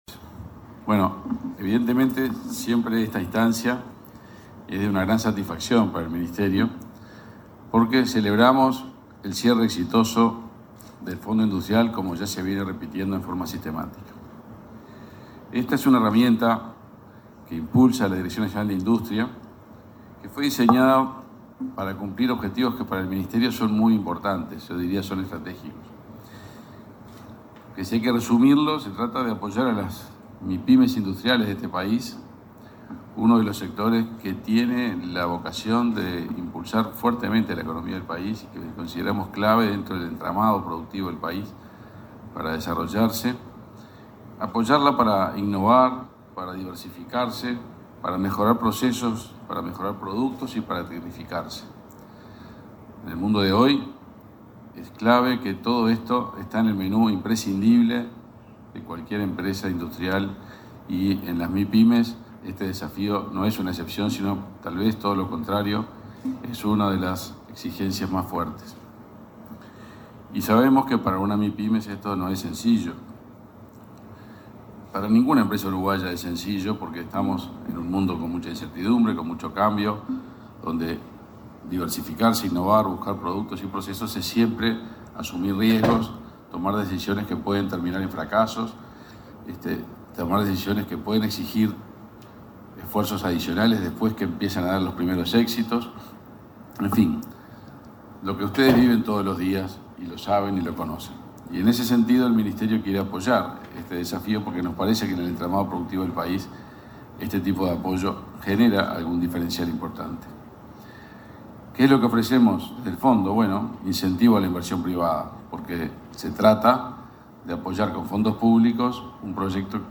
Palabra de autoridades en acto del Ministerio de Industria
Este viernes 16, el ministro, Omar Paganini; el subsecretario, Walter Verri; el presidente del Laboratorio Tecnológico del Uruguay, Ruperto Long; y la